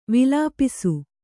♪ vilāpisu